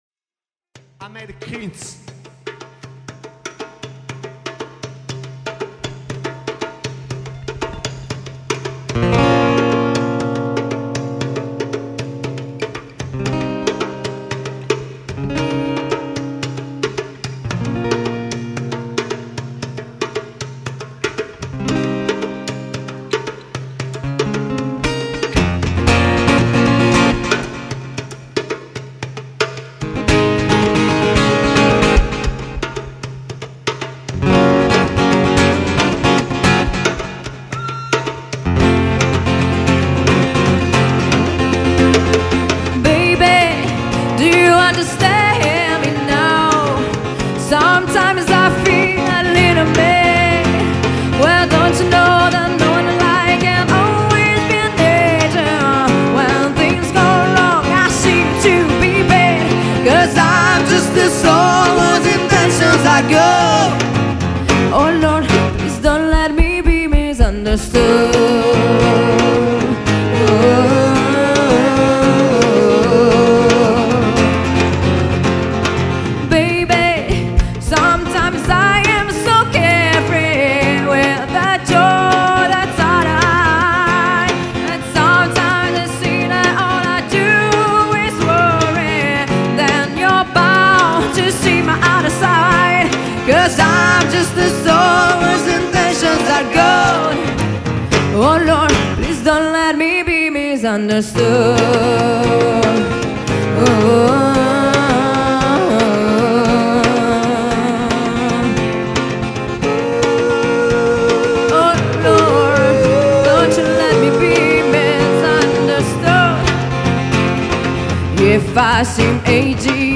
cover di vario genere riarrangiate in chiave acustica
Live @ Galere, RE